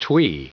Prononciation du mot twee en anglais (fichier audio)
Prononciation du mot : twee